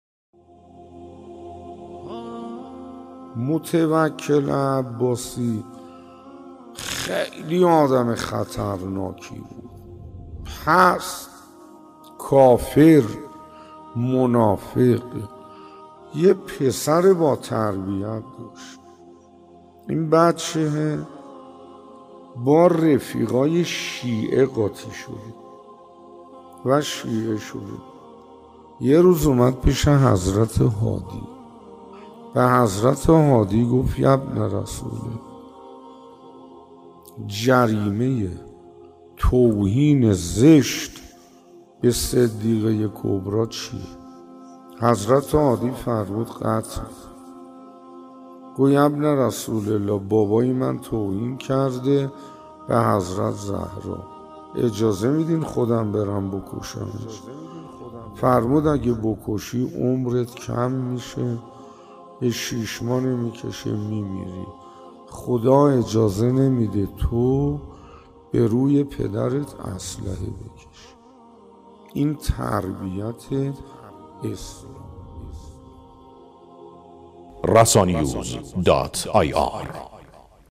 منبر سخنرانی